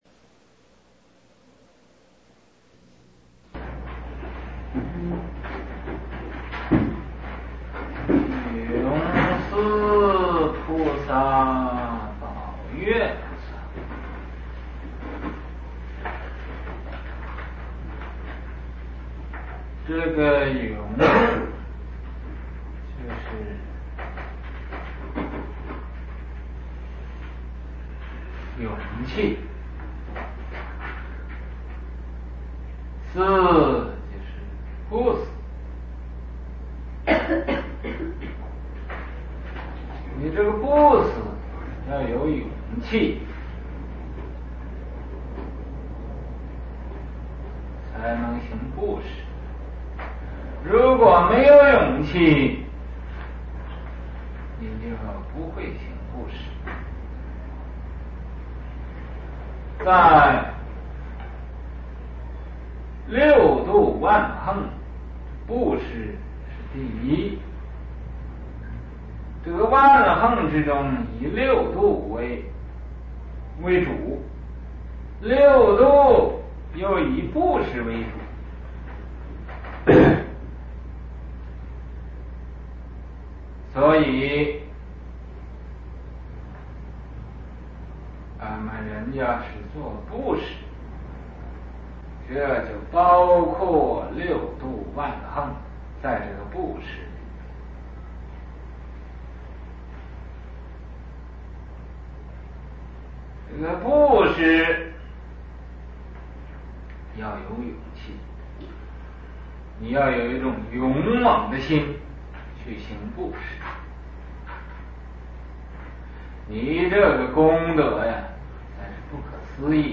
佛學講座